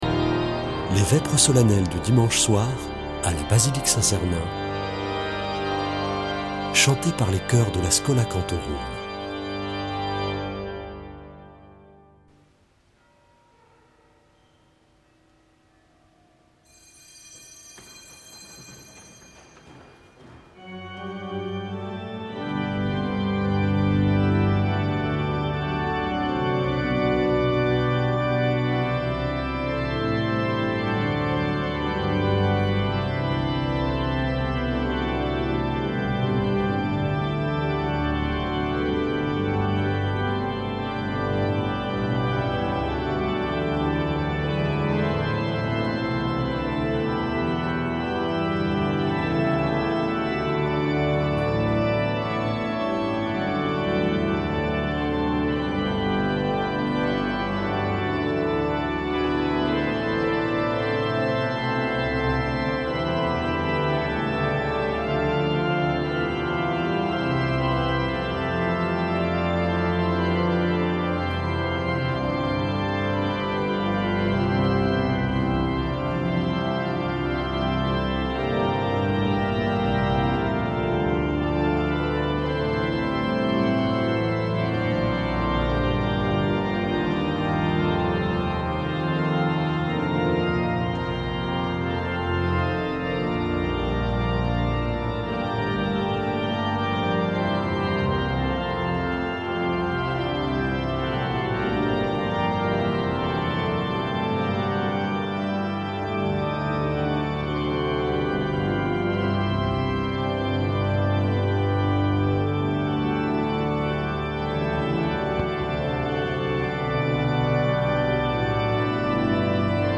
Vêpres de Saint Sernin du 08 sept.
Une émission présentée par Schola Saint Sernin Chanteurs